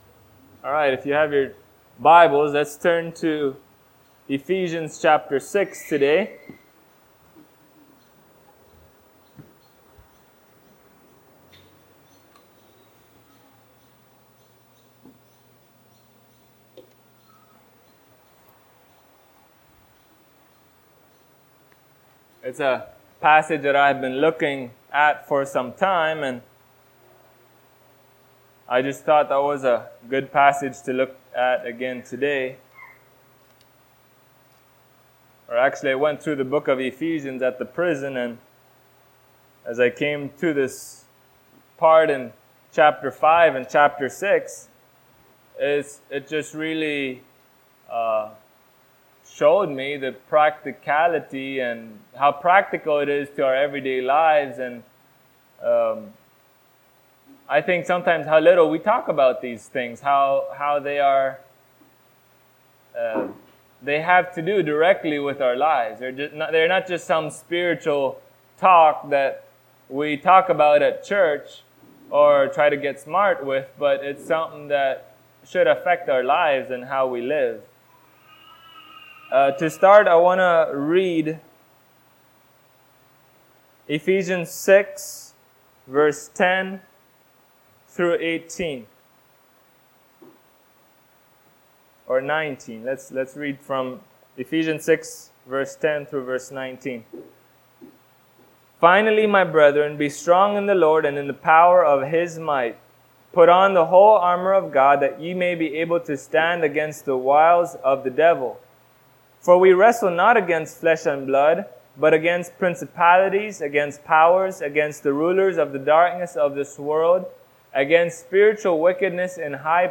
Passage: Ephesians 6:10-19 Service Type: Sunday Morning Topics